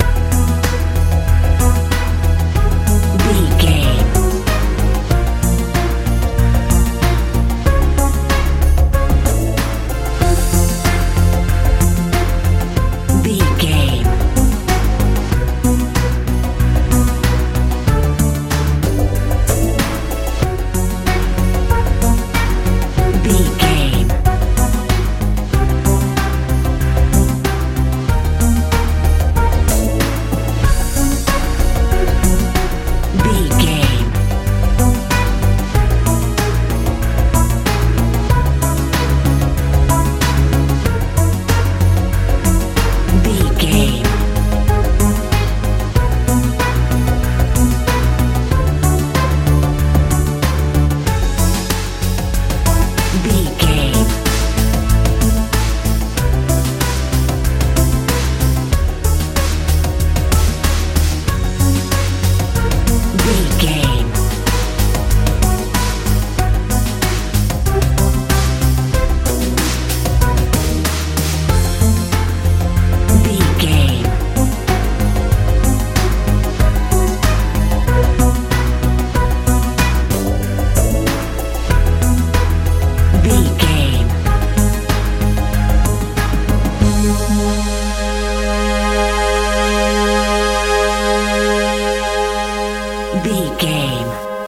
modern dance
Ionian/Major
A♭
strange
dramatic
synthesiser
bass guitar
drums
80s
90s